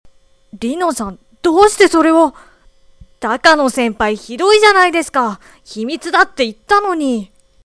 １６歳／男
■　Voice　■